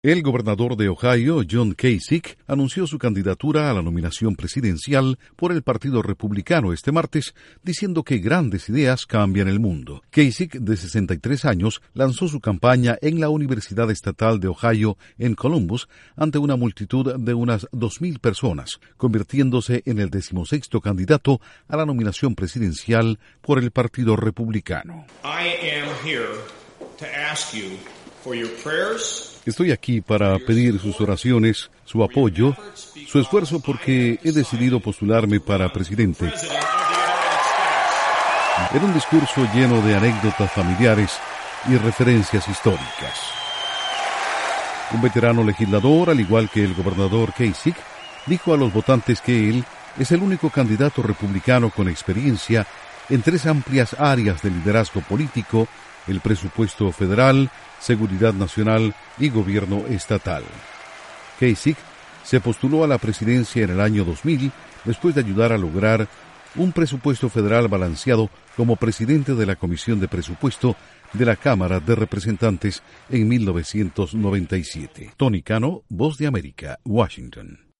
Gobernador de Ohio es el aspirante número dieciséis de los republicanos a la Casa Blanca… se trata de John Kasich quien ya se había postulado en el año 2000. Informa desde la Voz de América en Washington